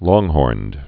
(lônghôrnd, lŏng-)